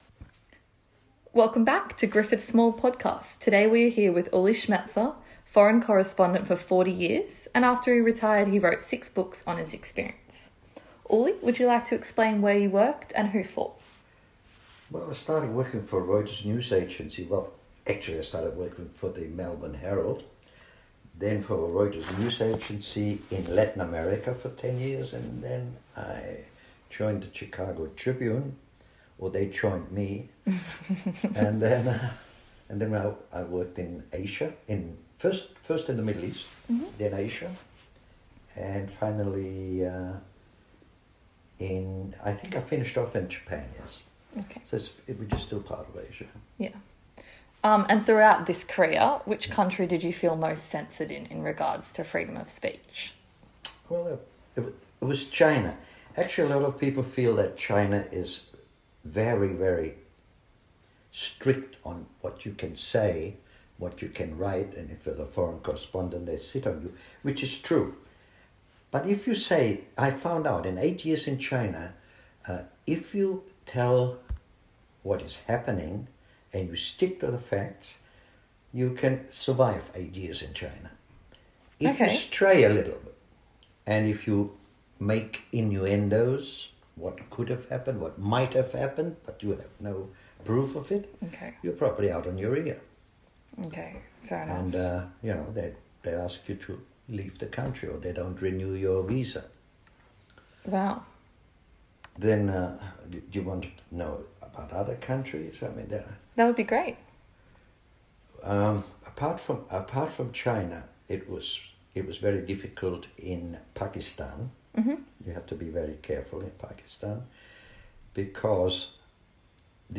Welcome to Griffith SMALL (Social Media And Law Livestream) where we interview experts on a range of aspects of social media law.